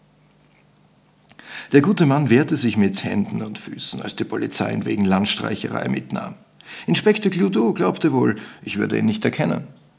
sailor_old_matt_0.wav